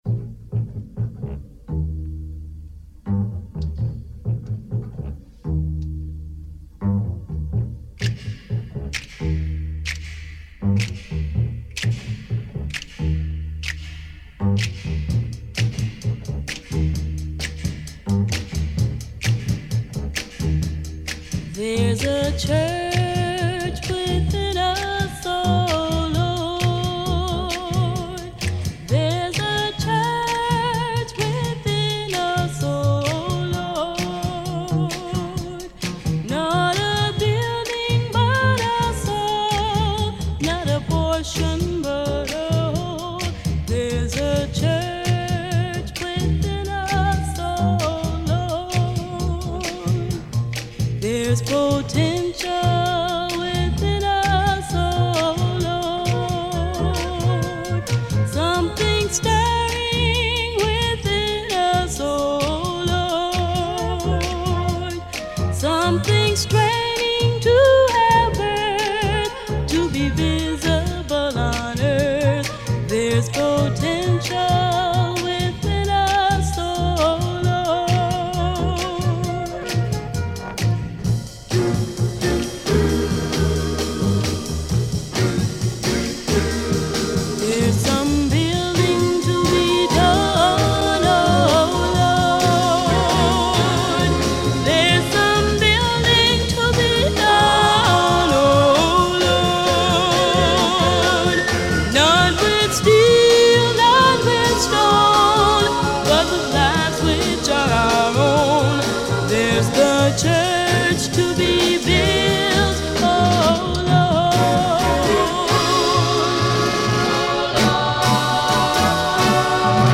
Superb gospel - soul - spiritual jazz album
wonderful female vocals